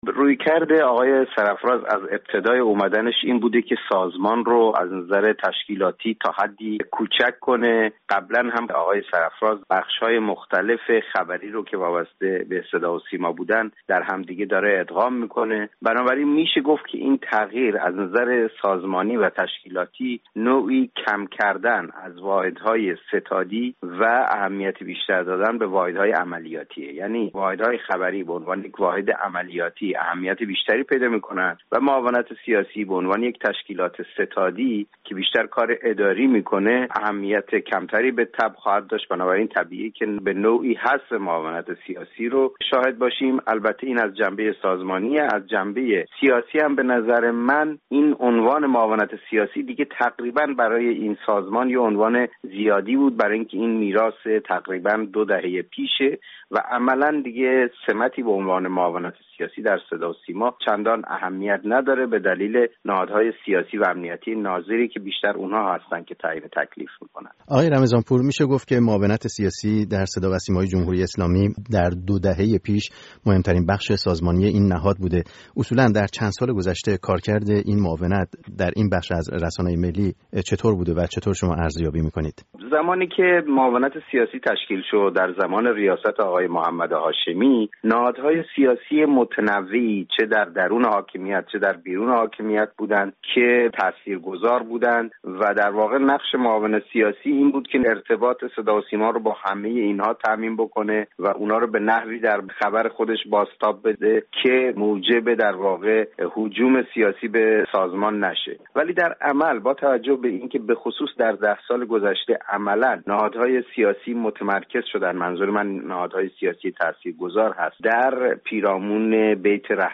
گفت وگوی